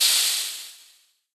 steam-burst-03.ogg